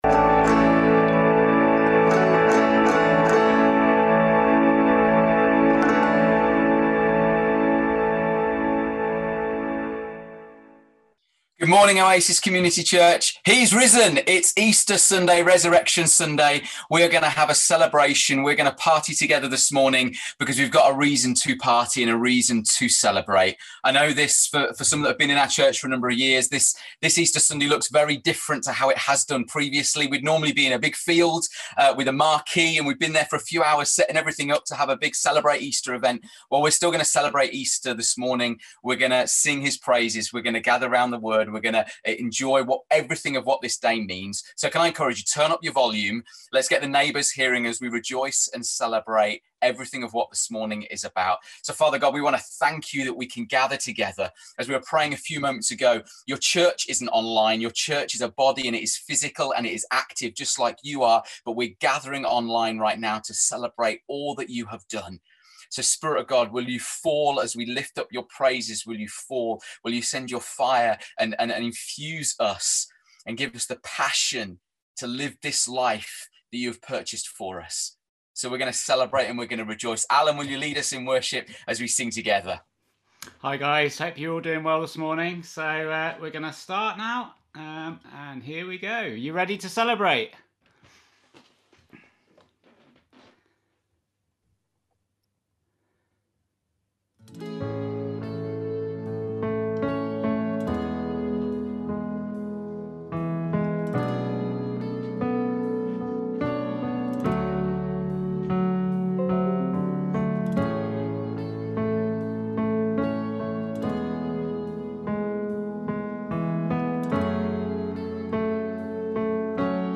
Listen again to our church online Easter Sunday Gathering at Watch at :